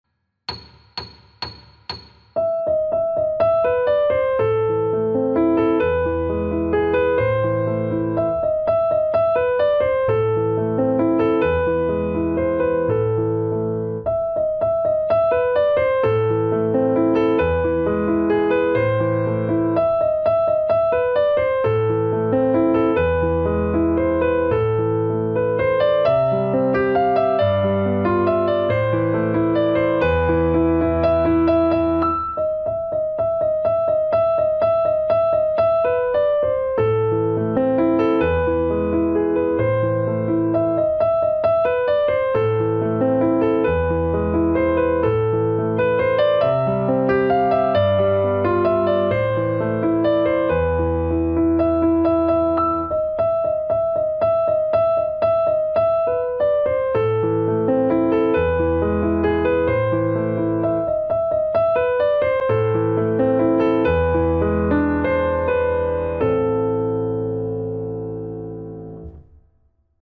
Pro-Elisku-vysledek-130-metronom.mp3